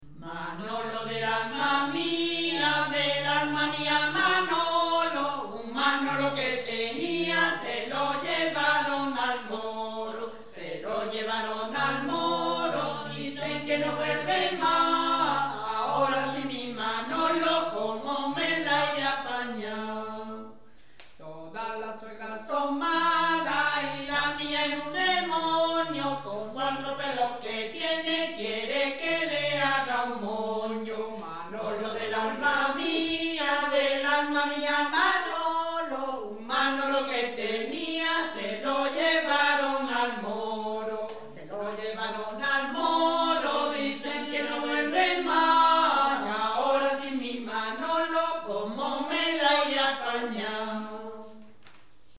A continuación os transcribimos algunas de ellas y las acompañamos del sonido original en las gargantas de algunos de aquellos "mozos" que ya no lo son tanto...